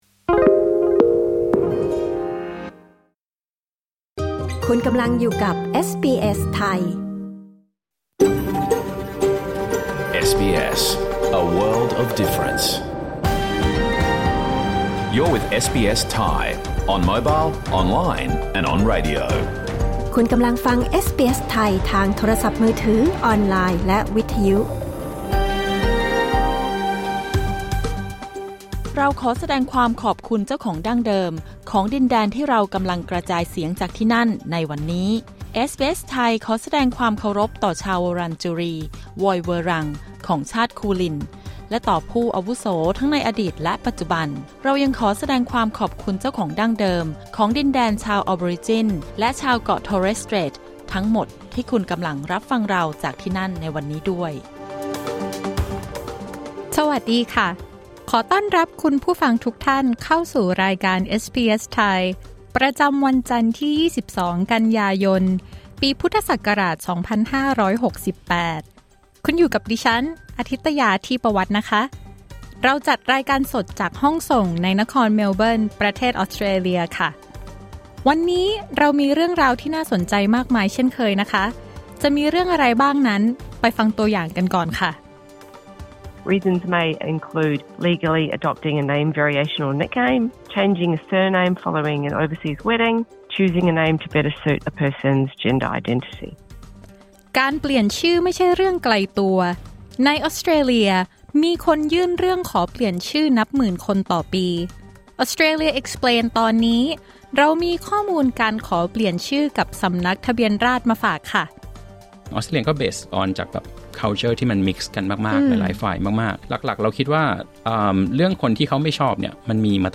รายการสด 22 กันยายน 2568